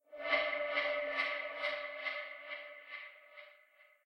scpcb-godot/SFX/Ambient/Zone1/Ambient7.ogg at c2afe78d7f60ab16ee83c3070b724c6066b420c6
Ambient7.ogg